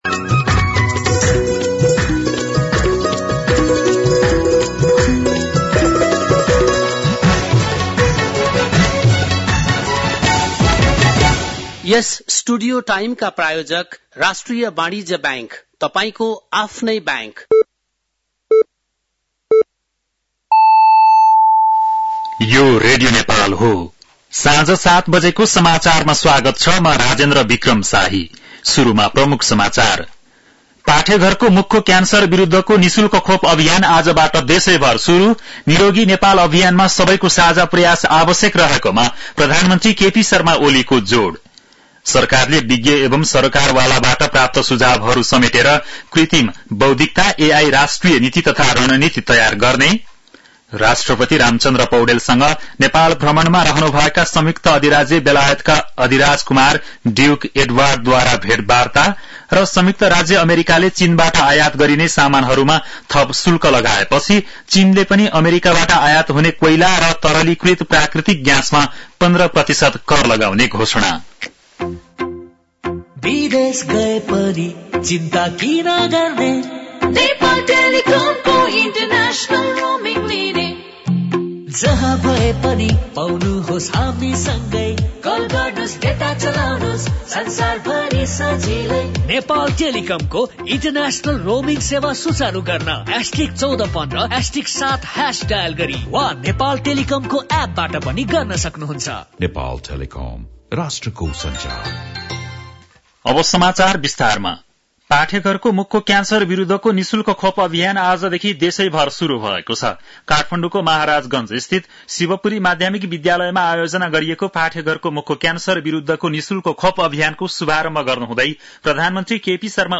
बेलुकी ७ बजेको नेपाली समाचार : २३ माघ , २०८१
7-PM-Nepali-News-10-22.mp3